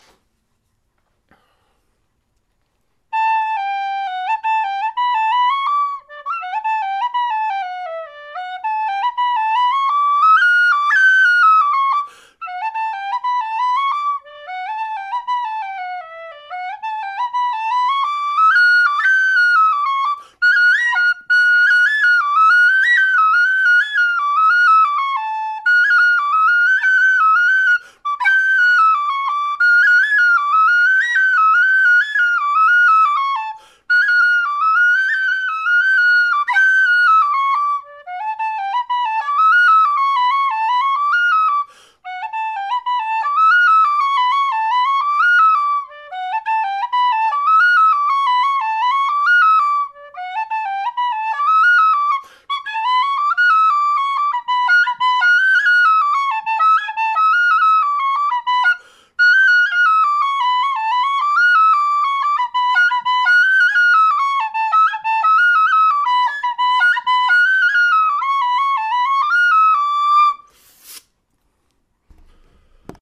I’m not the best player around, but here are a few clips, warts and all, of my whistles